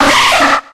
59a086e9f7cca0ffa2a0109cf987da125b8d72c7 infinitefusion-e18 / Audio / SE / Cries / NIDORINA.ogg infinitefusion 57165b6cbf 6.0 release 2023-11-12 15:37:12 -05:00 9.1 KiB Raw History